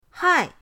hai4.mp3